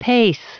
Prononciation du mot pace en anglais (fichier audio)
Prononciation du mot : pace